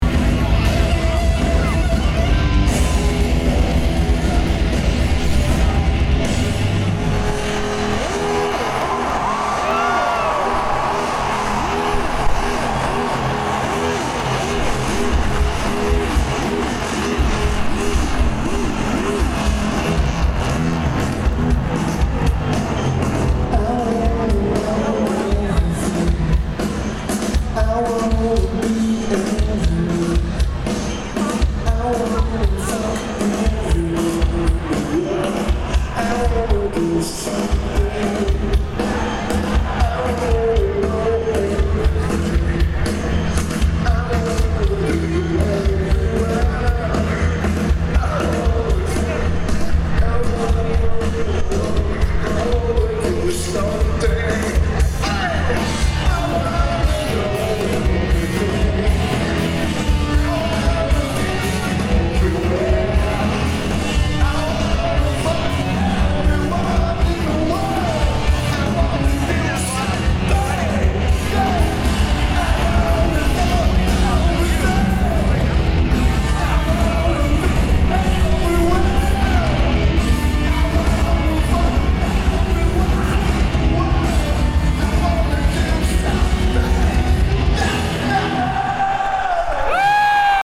Verizon Wireless Music Center
Lineage: Audio - AUD (Tascam DR-07)